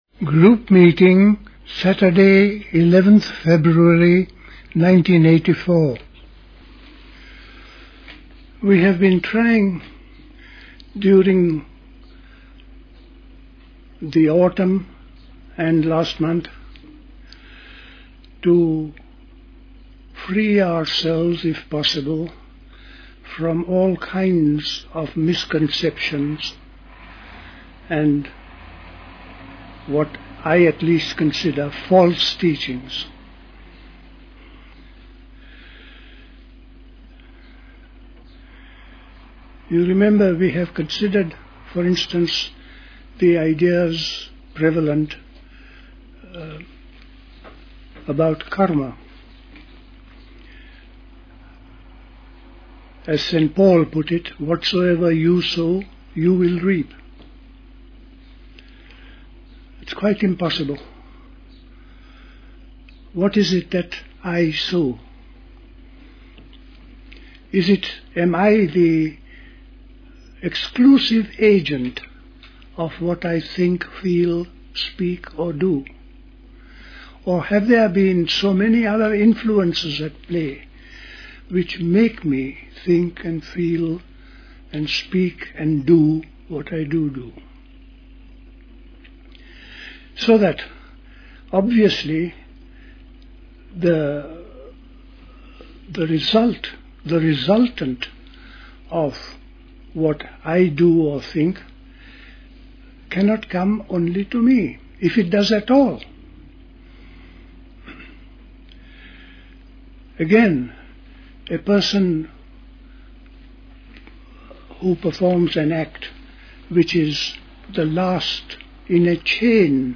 The Dilkusha Talks